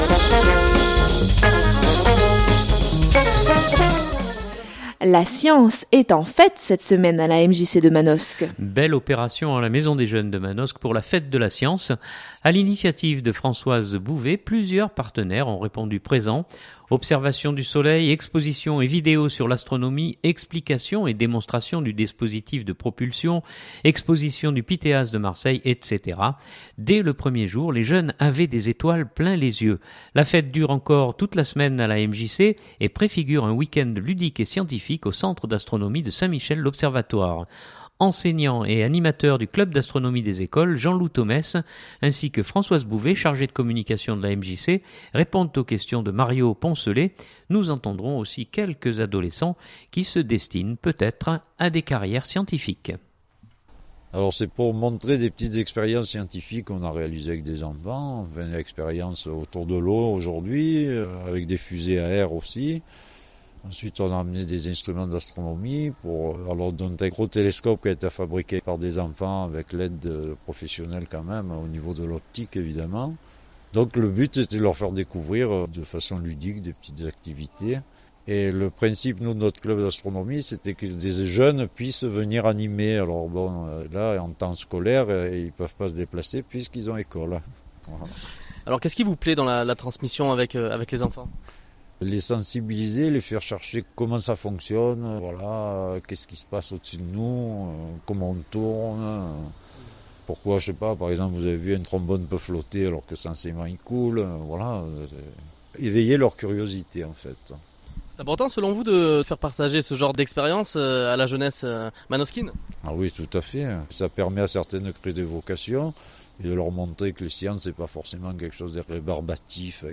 nous entendrons aussi quelques adolescents qui se destinent peut-être à des carrières scientifiques.